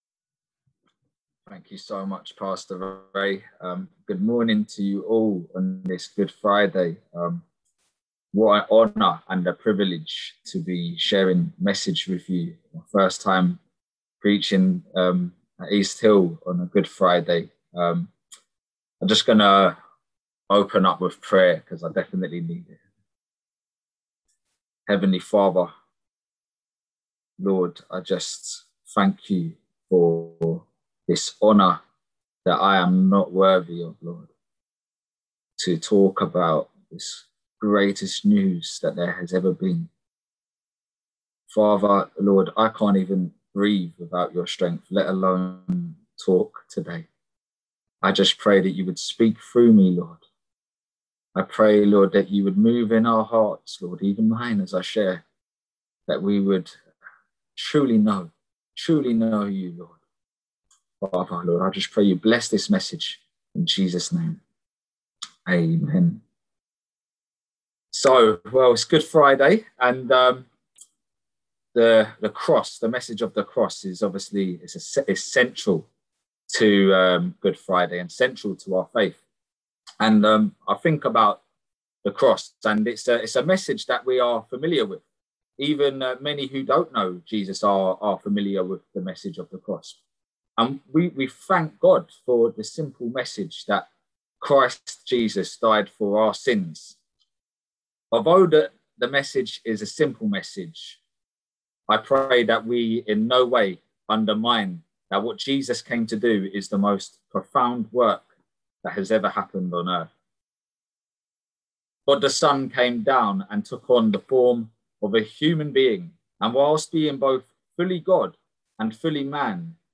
Below is the recording of the sermon for this week.